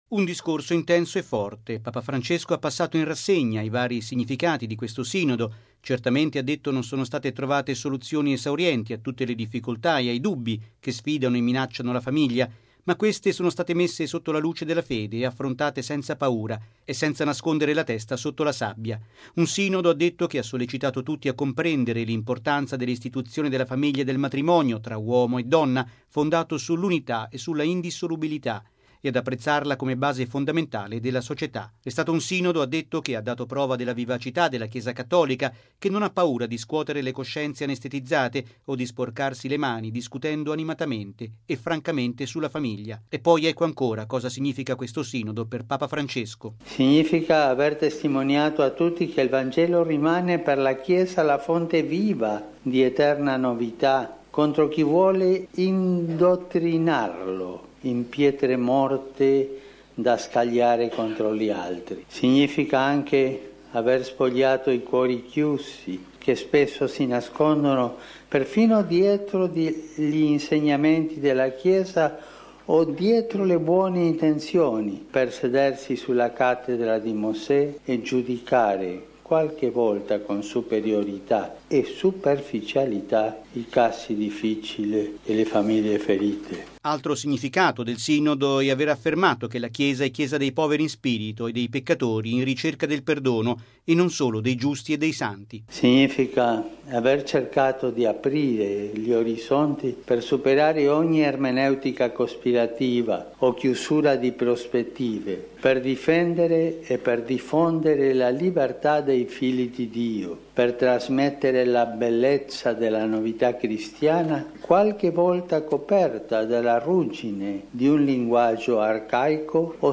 Bollettino Radiogiornale del 25/10/2015